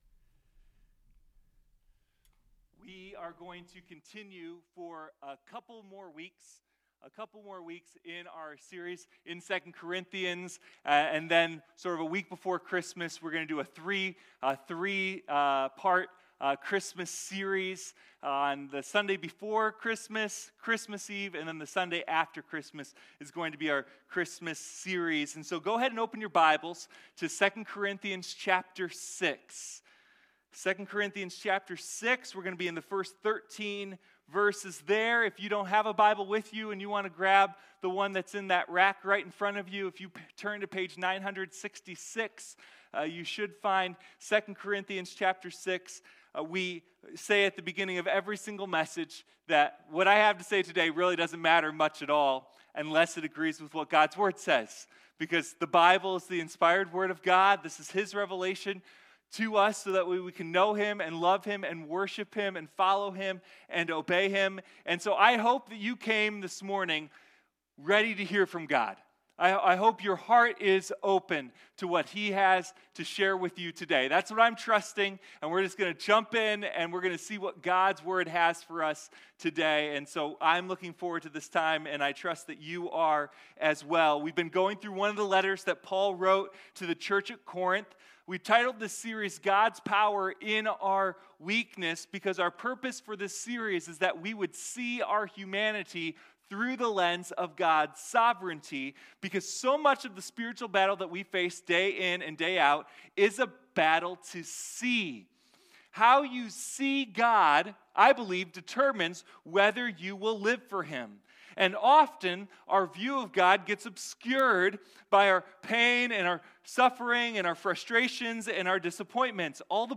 Sermon1.mp3